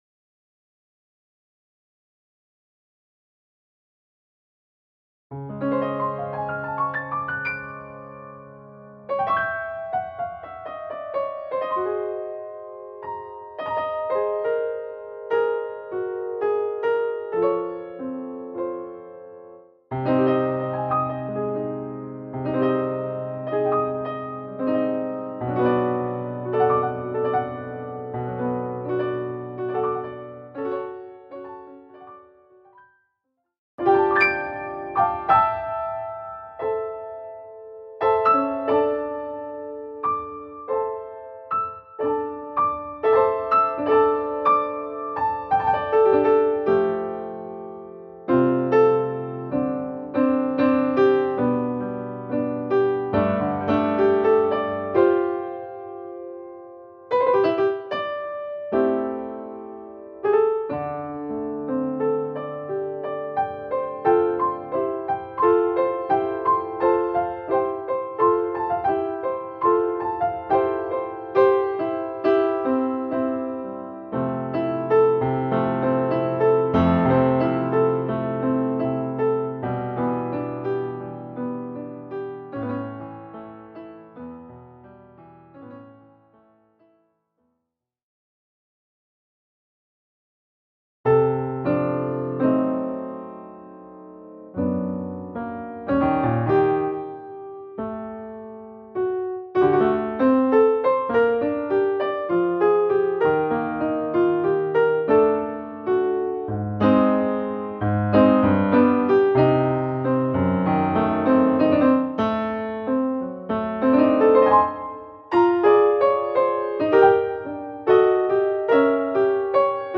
New piano album, soon at the streaming services .